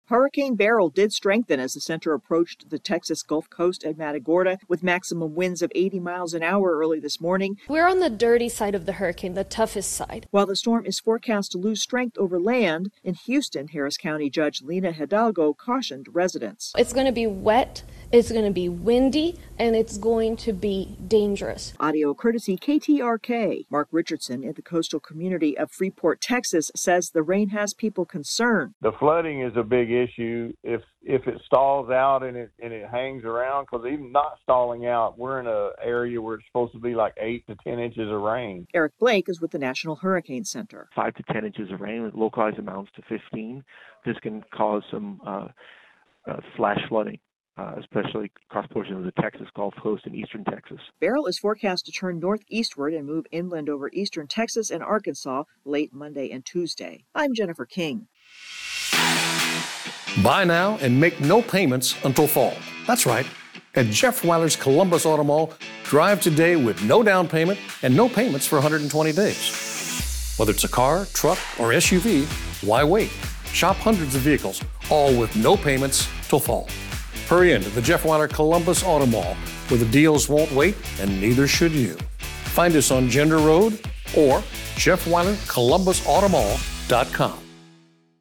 Beryl bears down on Texas. AP correspondent